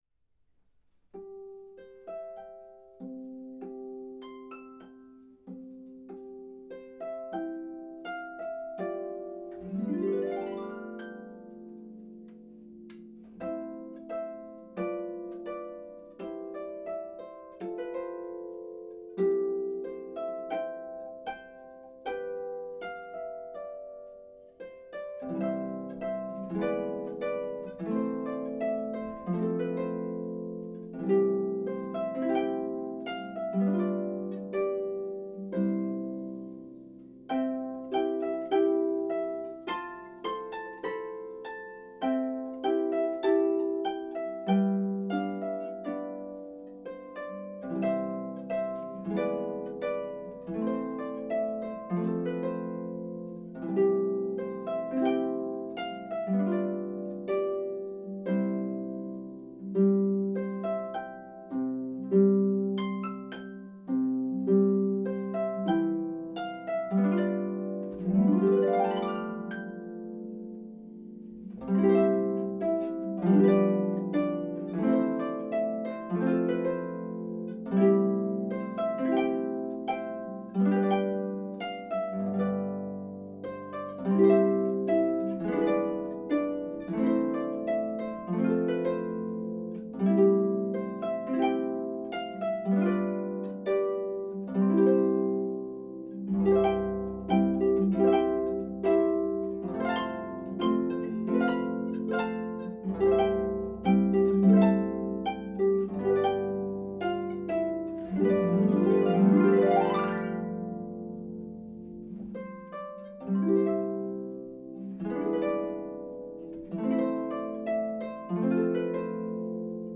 traditional folk song
for solo pedal harp